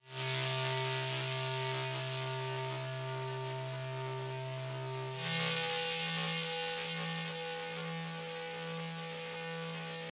描述：静电垫环
Tag: 90 bpm Cinematic Loops Pad Loops 1.79 MB wav Key : E